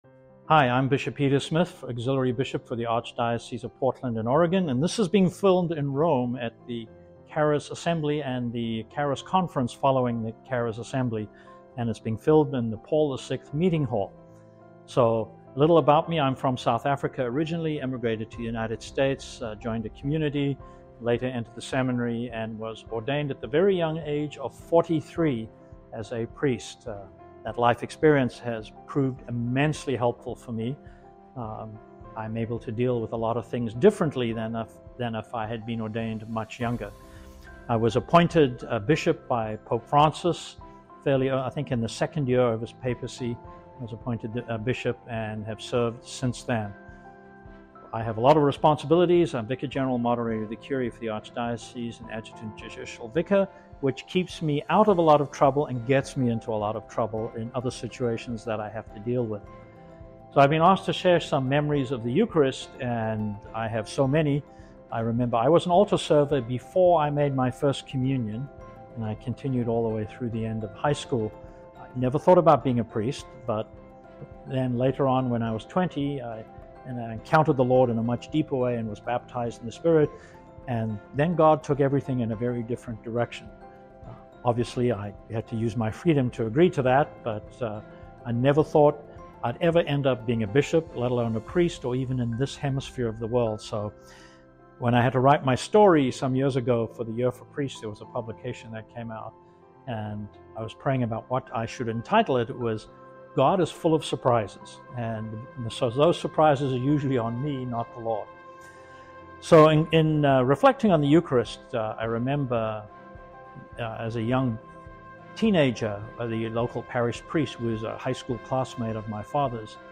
(full interview length) (radio ready 4 min)